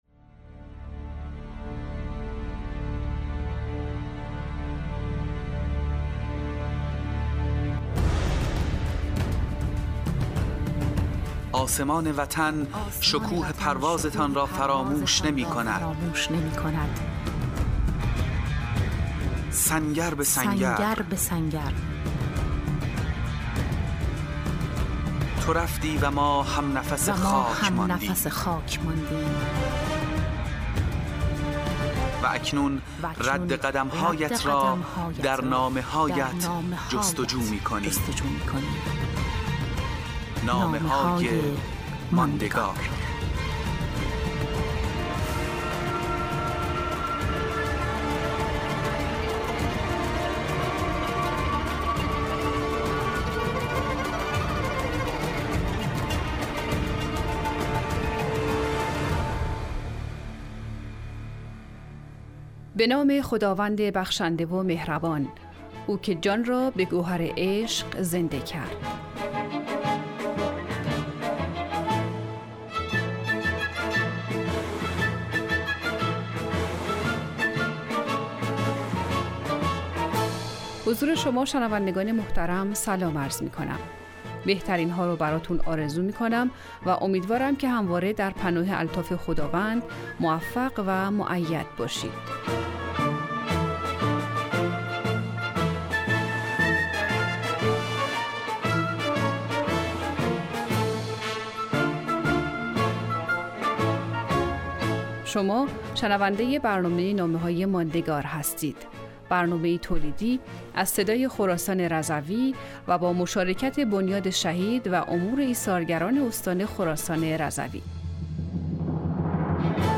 نوید شاهد : مجموعه 25 برنامه رادیویی با موضوع نامه های به یادگار مانده از شهدای استان خراسان رضوی